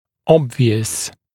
[‘ɔbvɪəs][‘обвиэс]очевидный, заметный